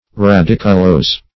Search Result for " radiculose" : The Collaborative International Dictionary of English v.0.48: Radiculose \Ra*dic"u*lose`\ (r[.a]*d[i^]k"[-u]*l[=o]s`), a. (Bot.)
radiculose.mp3